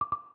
VideoPause.ogg